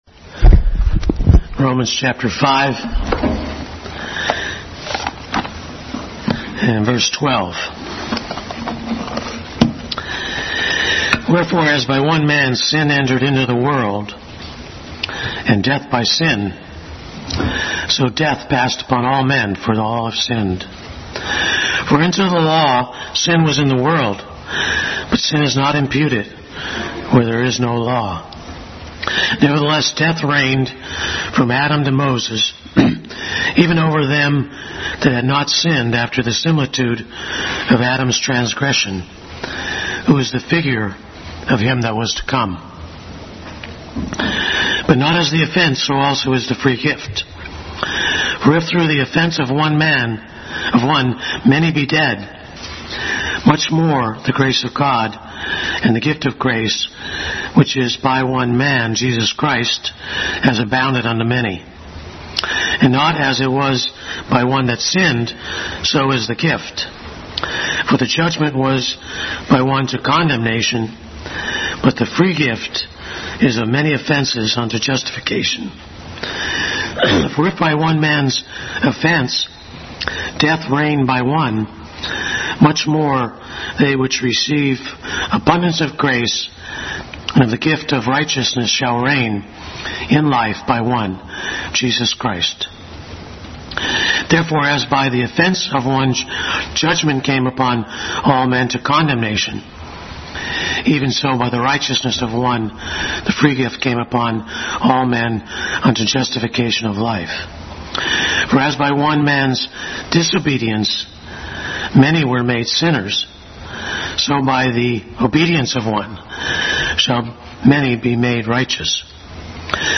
Bible Text: Romans 5:12-21, Genesis 3:22-24 | Adult Sunday School class continued study in the book of Romans.